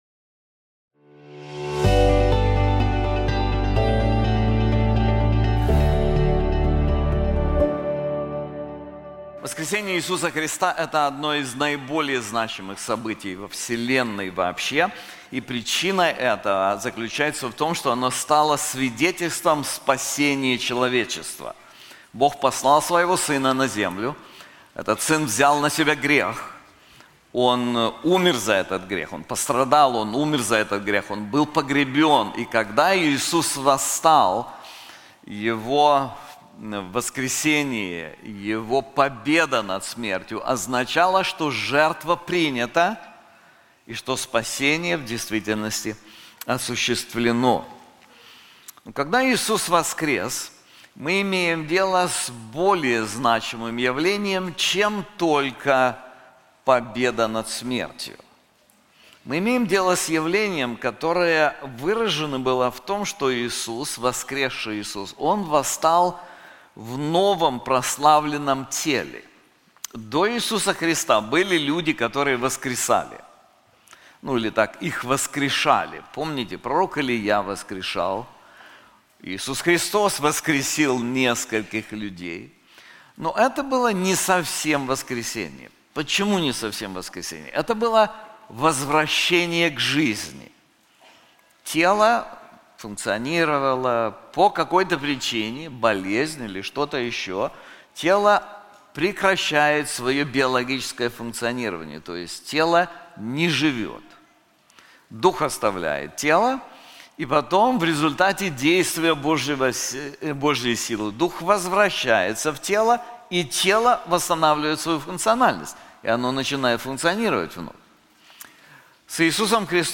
This sermon is also available in English:The Victory of the Risen One • Isaiah 53:10-12